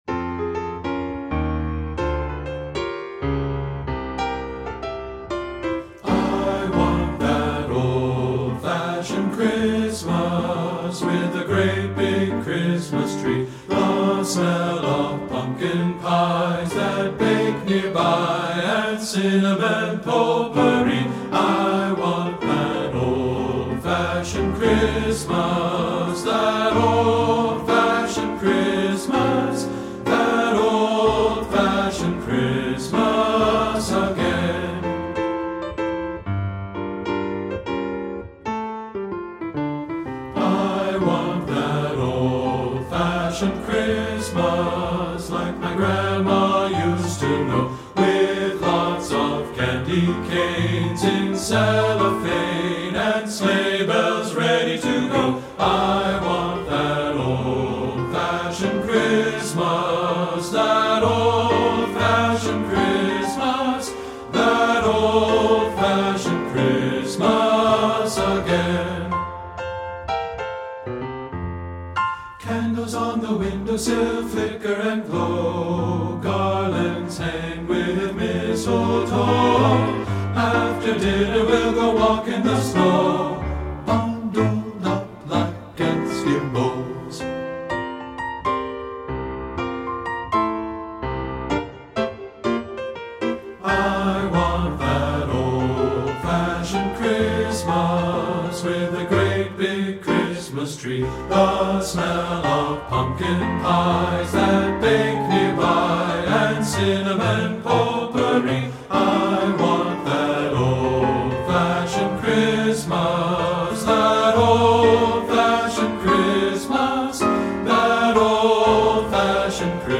Voicing: TB